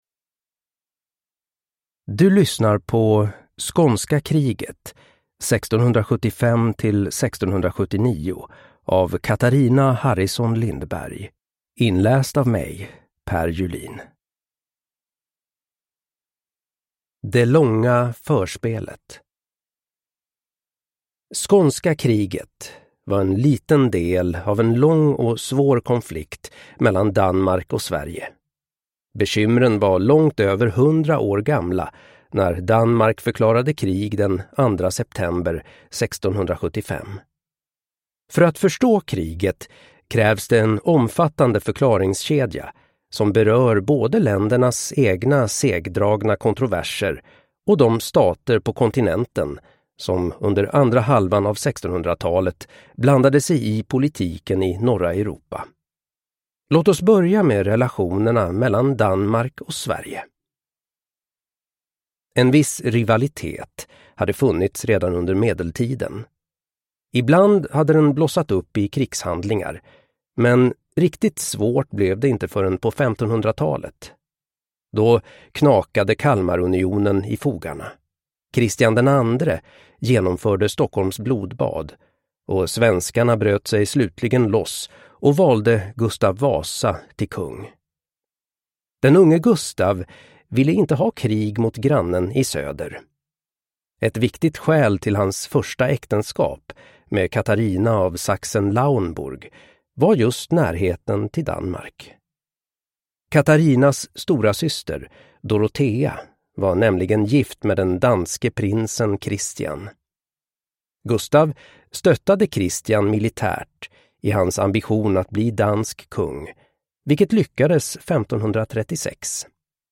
Skånska kriget 1675–1679 – Ljudbok